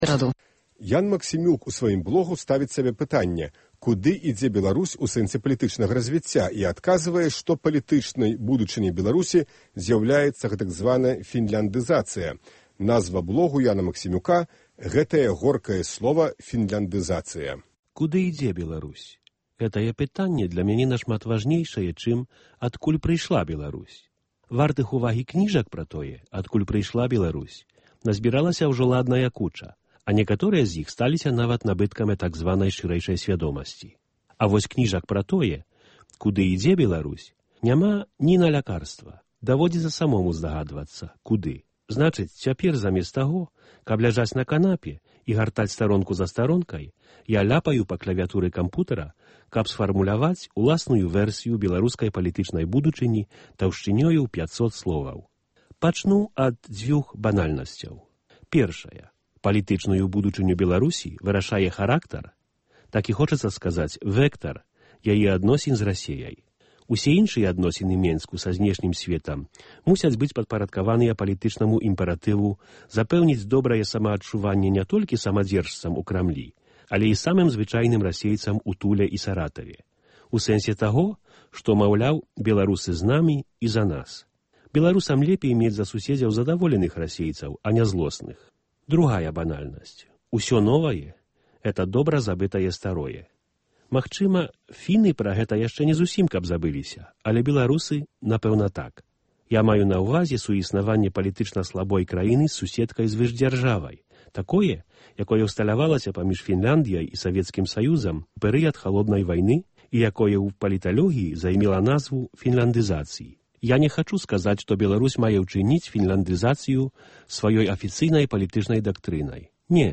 Нашы блогеры чытаюць свае тэксты